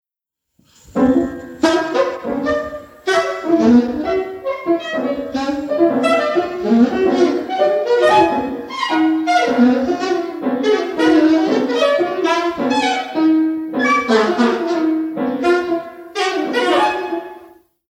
soprano saxophonist
pianist